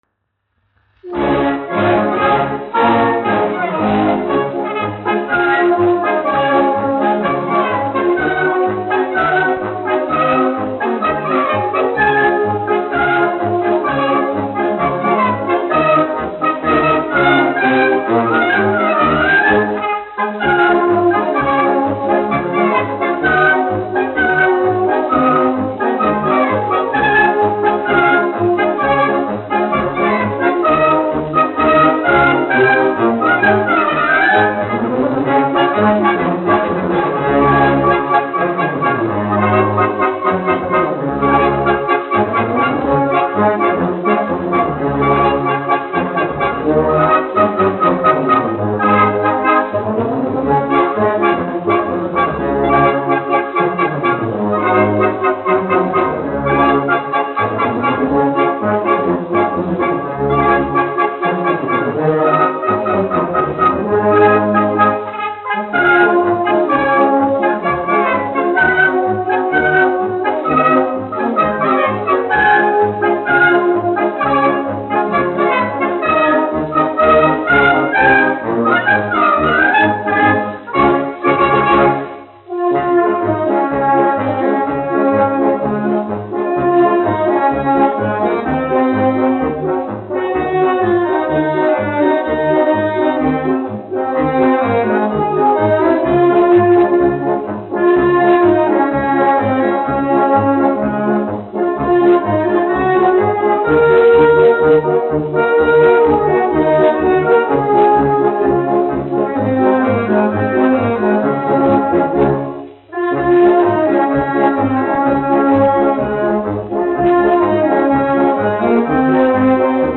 1 skpl. : analogs, 78 apgr/min, mono ; 25 cm
Marši
Pūtēju orķestra mūzika
Skaņuplate